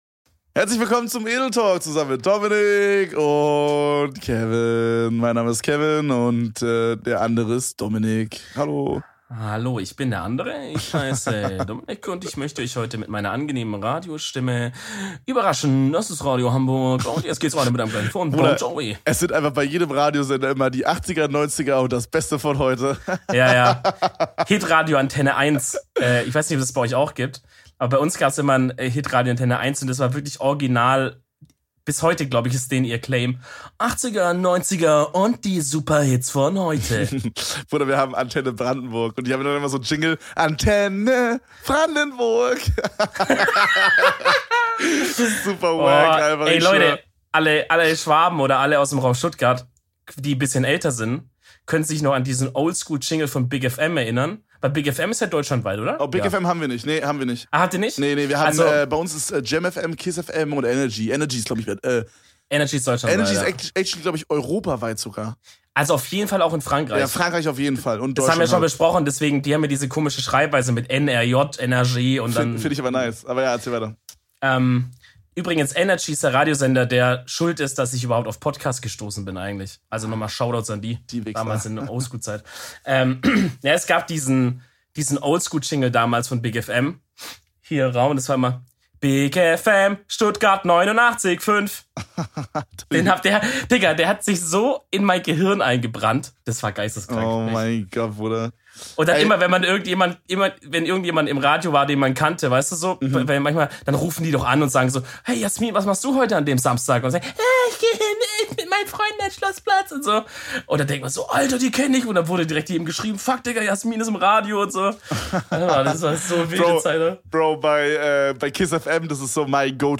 Die heutige Folge ist wild durch alle Themen unterwegs. Von Radiosendern und TV-Formaten machen sich die beiden Honigstimmchen mit euch auf die Reise durch eine Stunde gute Laune.